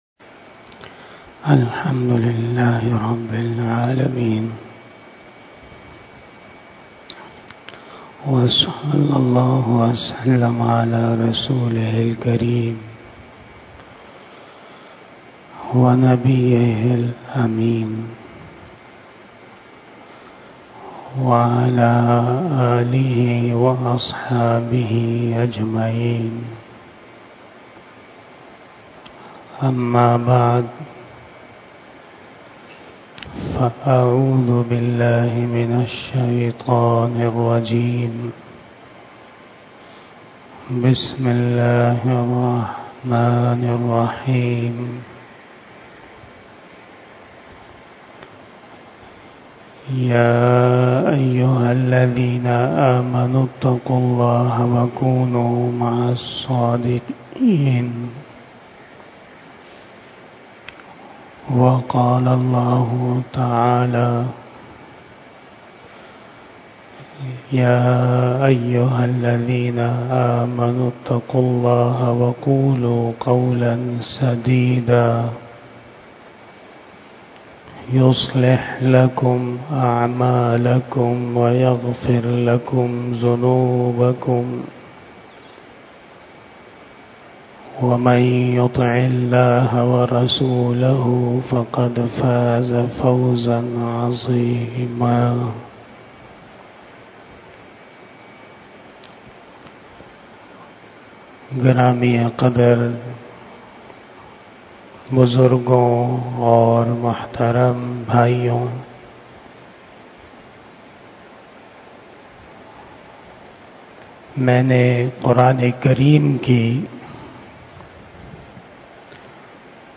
43 Bayan-E-Jummah 27 October 2023 (11 Rabi Us Sani 1445 HJ)